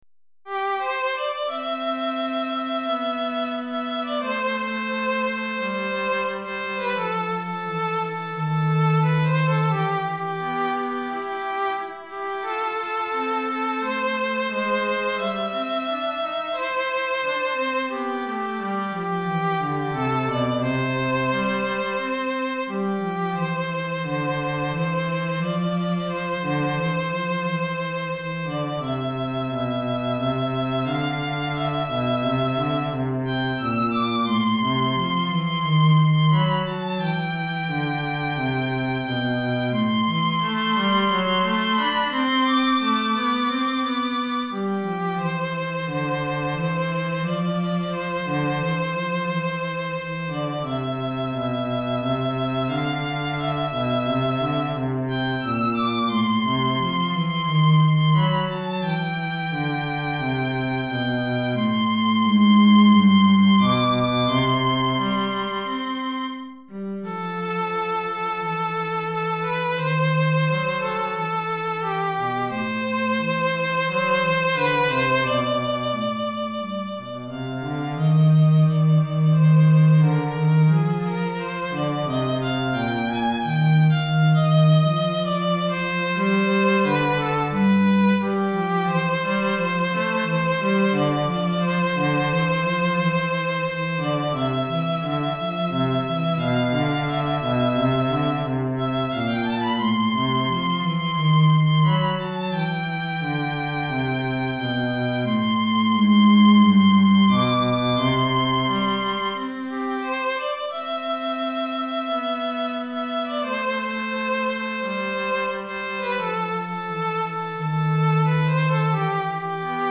Violon et Violoncelle